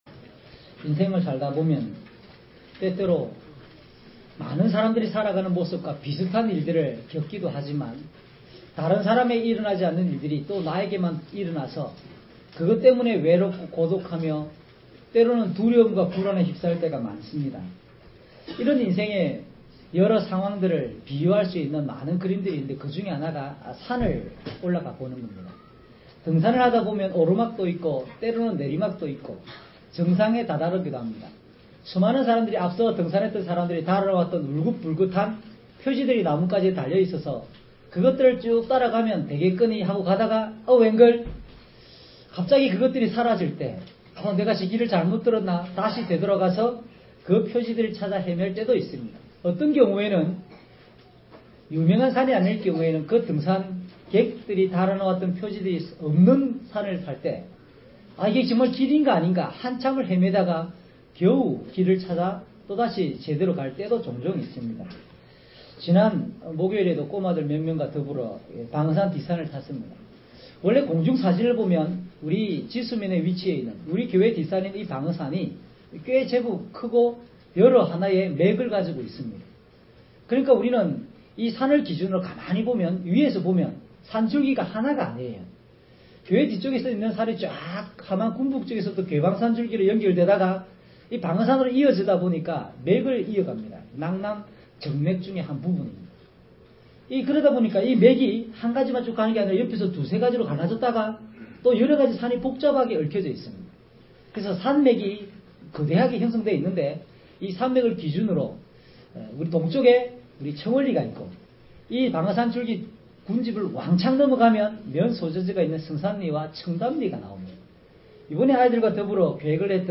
주일설교 - 09년 02월 22일 "무엇을 따라 가고 있습니까?"